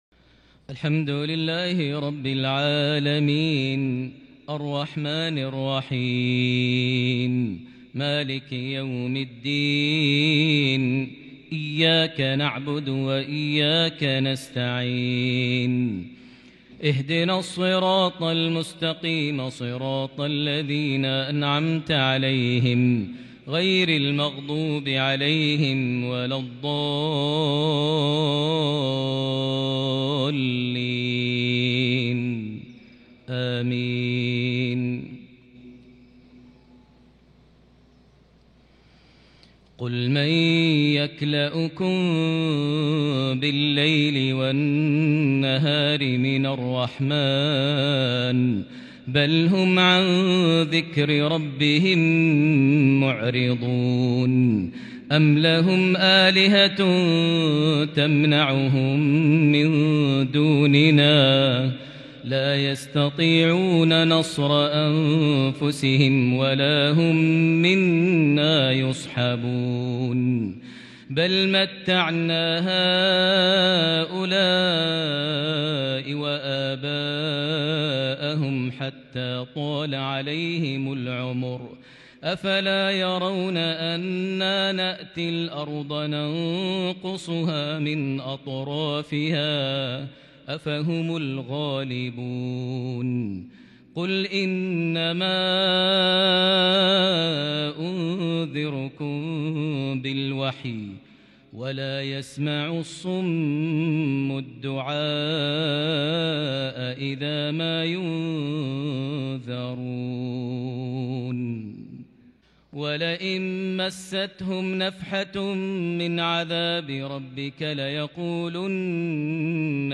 صلاة المغرب من سورة الأنبياء 19 جمادى الآخر 1442هـ | mghrip 1-2-2021 prayer from Surah Al-Anbiya 42 - 50 > 1442 🕋 > الفروض - تلاوات الحرمين